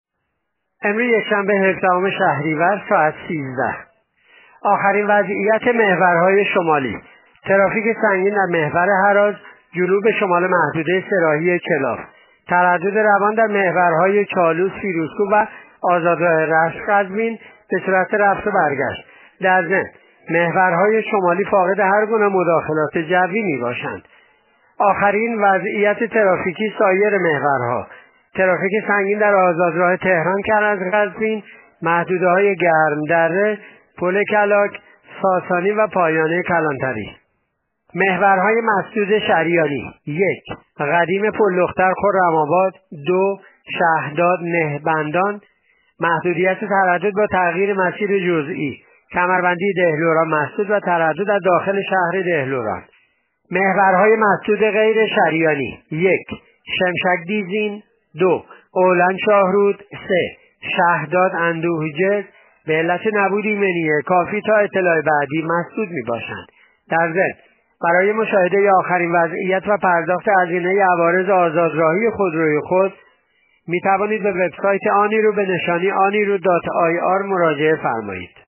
گزارش رادیو اینترنتی پایگاه خبری وزارت راه و شهرسازی از آخرین وضعیت ترافیکی جاده‌های کشور تا ساعت ۱۳ یکشنبه هفدهم شهریورماه/ ترافیک سنگین درمحورهراز / ترافیک سنگین در آزادراه تهران-کرج-قزوین